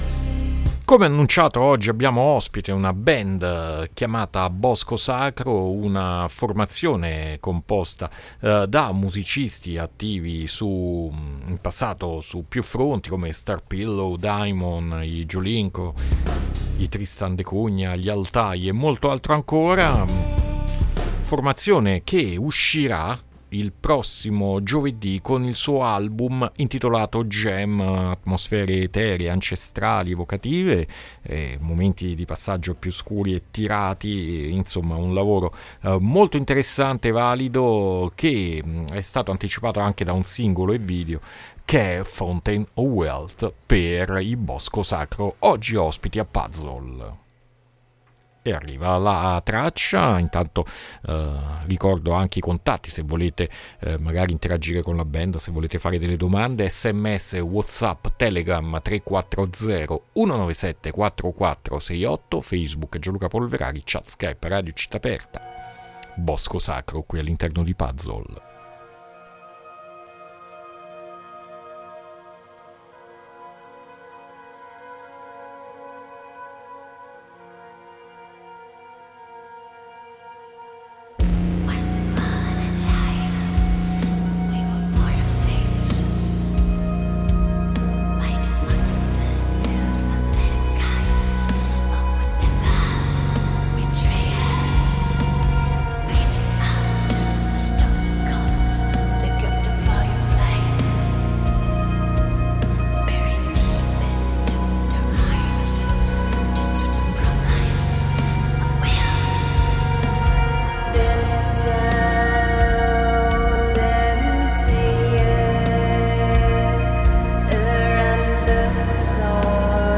INTERVISTA BOSCO SACRO A PUZZLE 6-2-2023